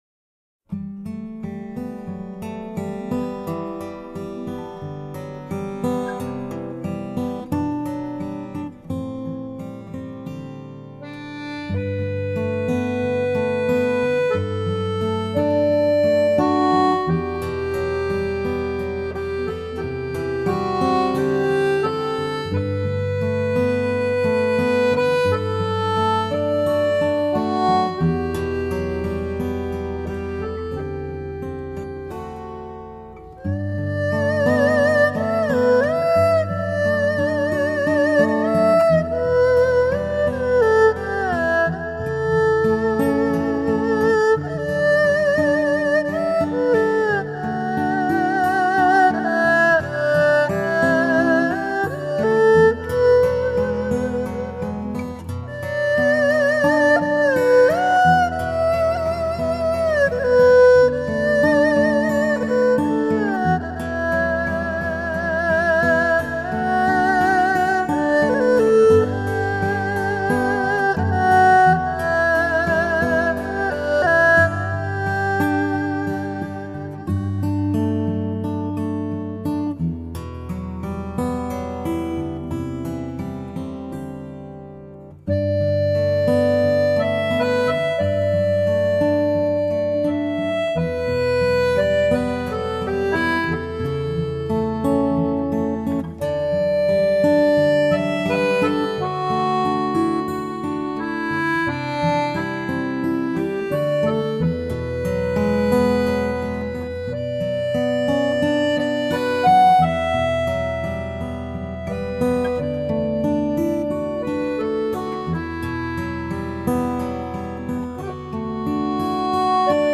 二胡 erfu